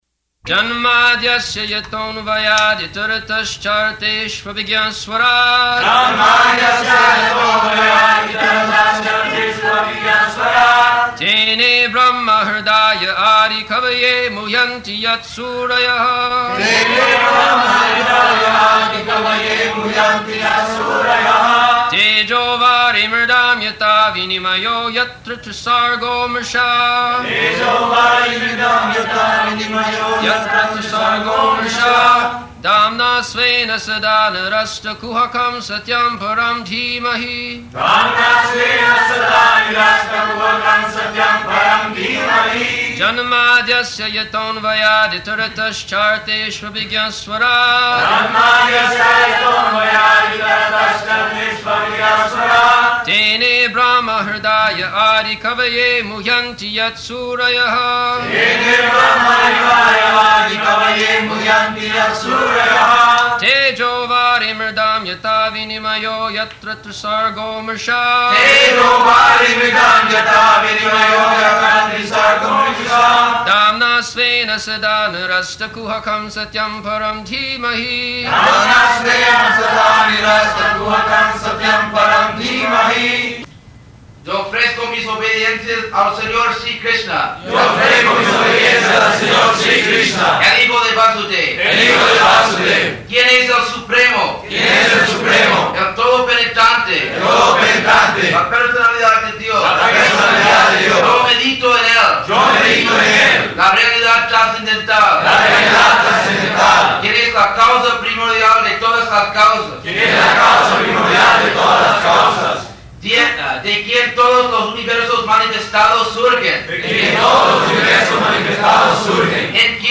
Location: Caracas
[leads chanting of verse]